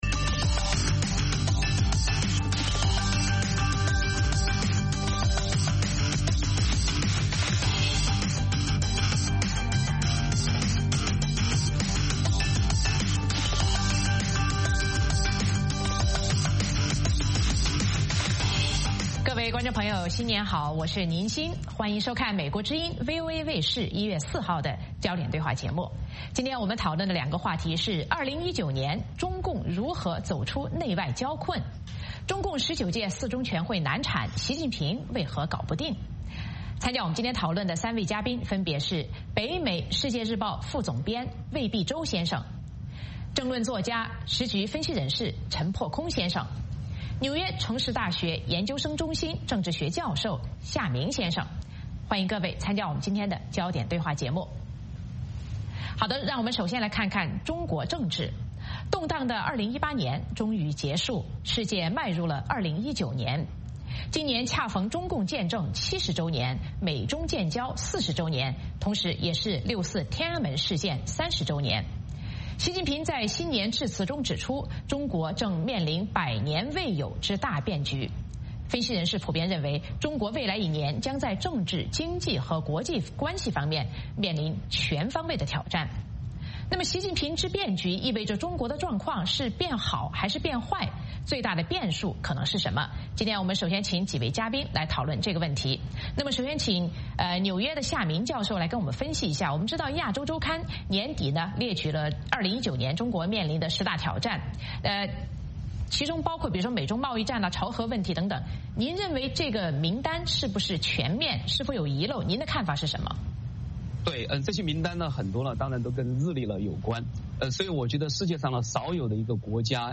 《焦点对话》节目追踪国际大事、聚焦时事热点。邀请多位嘉宾对新闻事件进行分析、解读和评论。